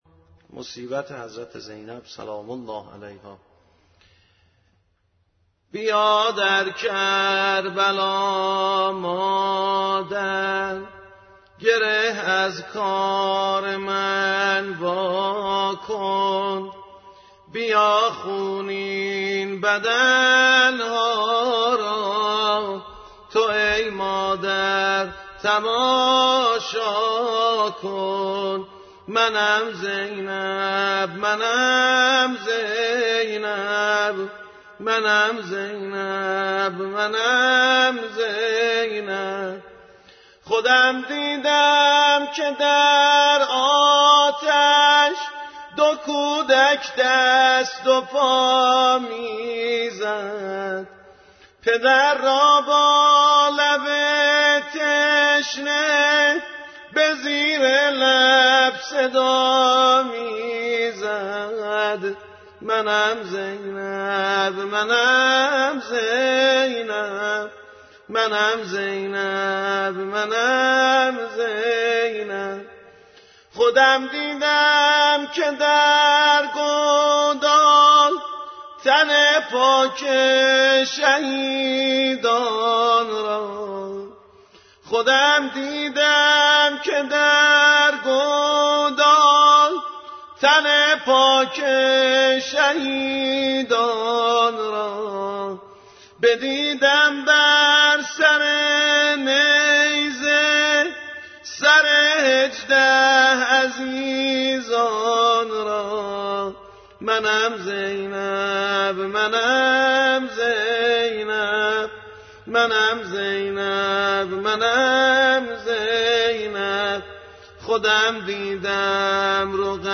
اشعار وفات حضرت زینب کبری(س),(بیا در کربلا مادر گره از کار من واکن)به همراه سبک سنتی